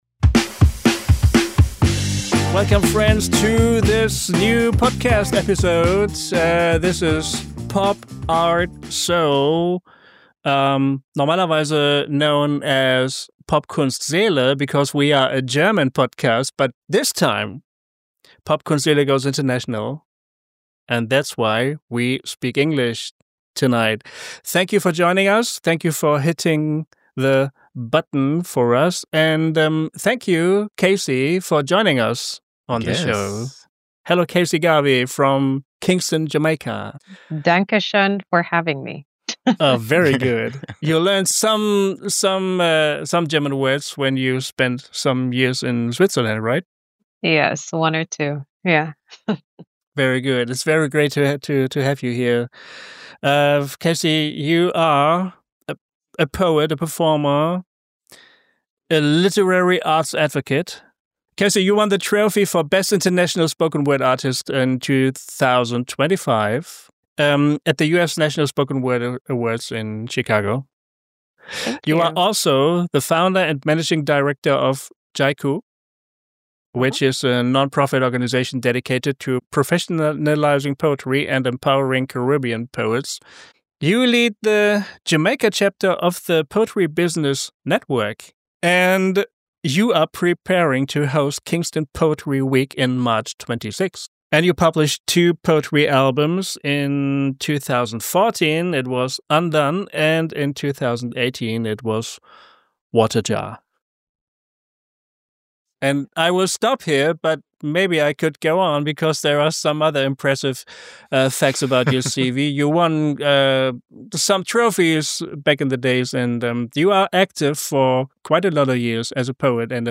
Enjoy this captivating conversation in English!